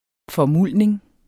Udtale [ fʌˈmulˀneŋ ]